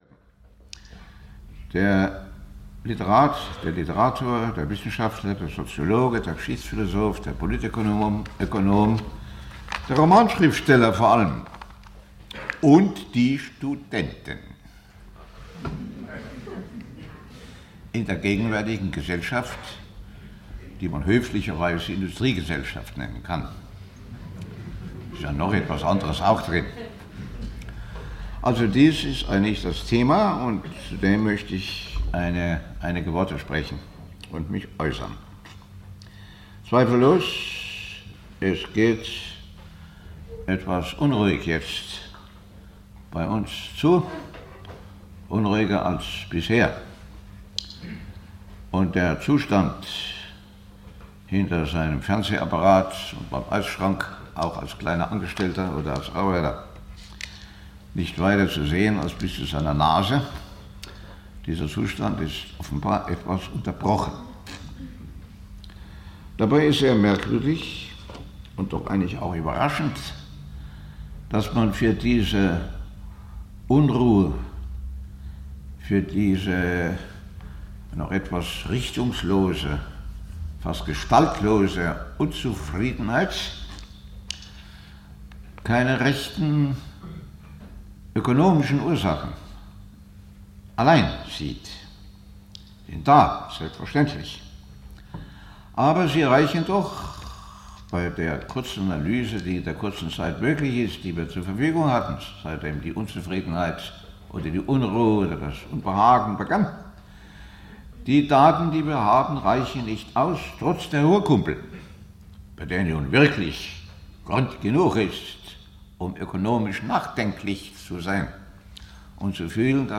Möglichkeiten der Utopie heute - Ernst Bloch - Hörbuch